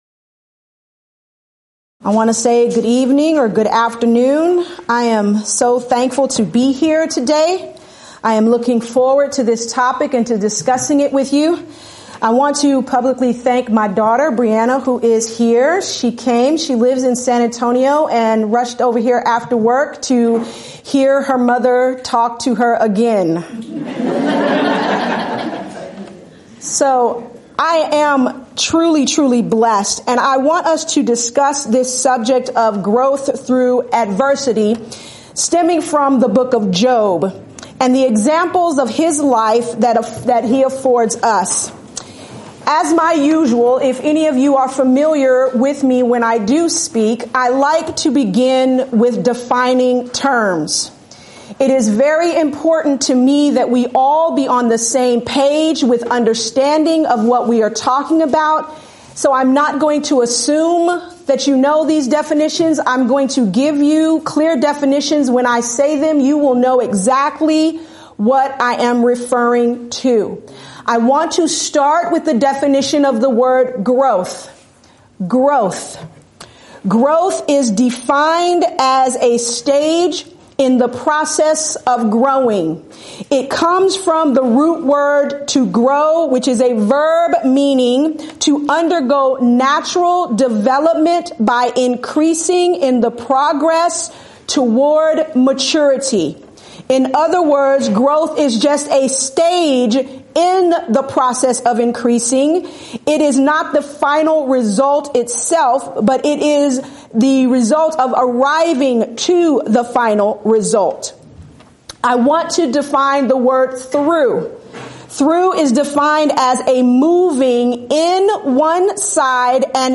Event: 17th Annual Schertz Lectures Theme/Title: Studies in Job
lecture